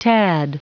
Prononciation du mot tad en anglais (fichier audio)
Prononciation du mot : tad